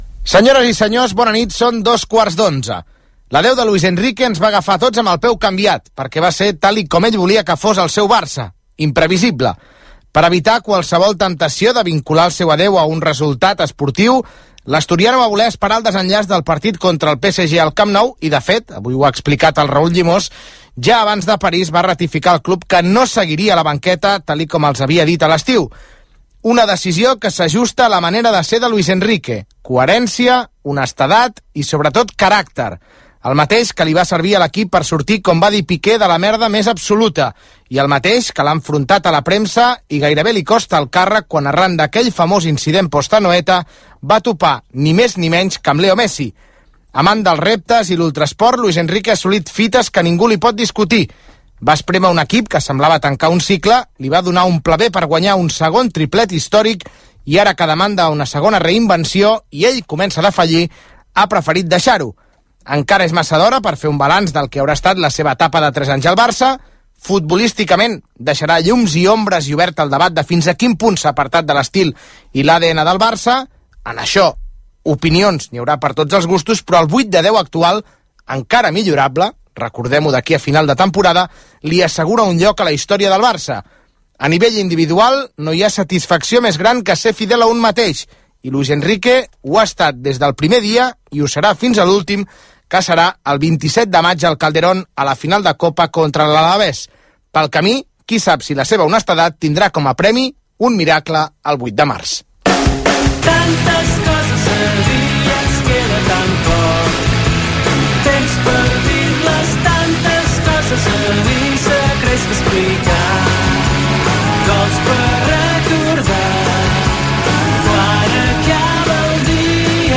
Hora, editorial sobre l'anunci del comiat de l'entrenador del F.C. Barcelona Luis Enrique, careta del programa, hora, sumari de continguts
Gènere radiofònic Esportiu